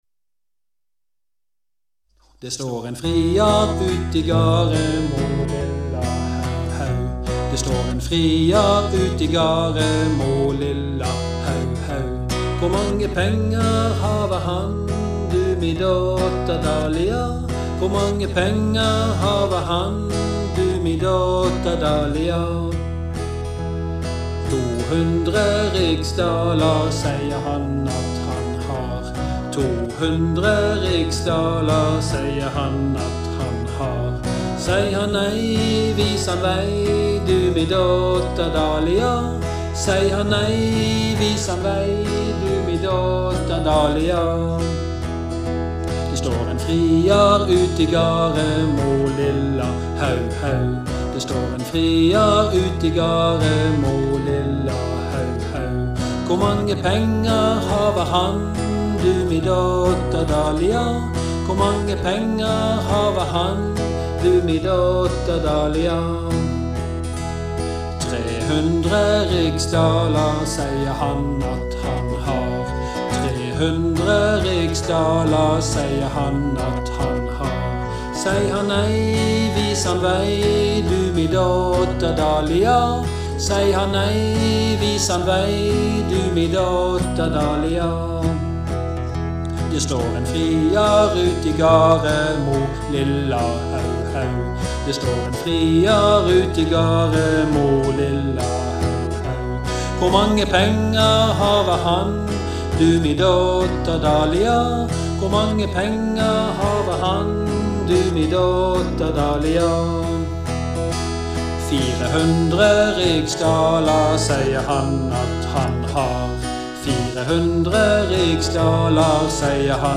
Song med vokal